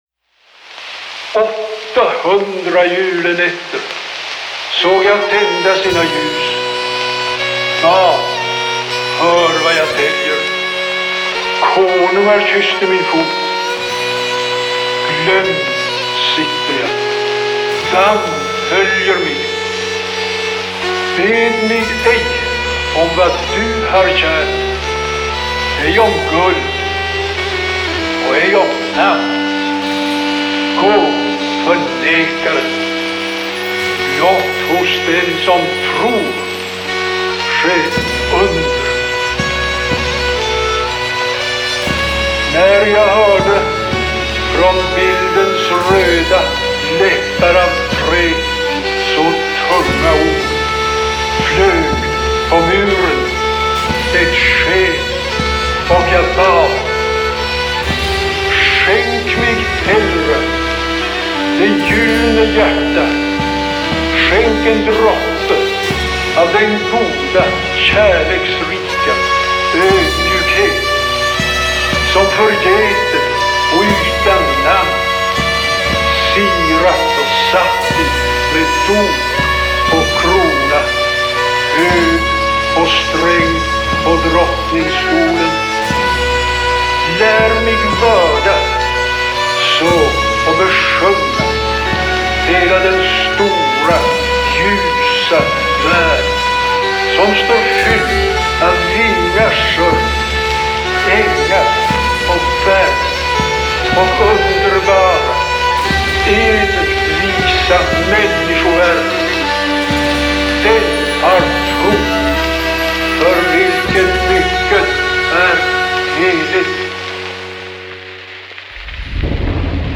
Style: Neofolk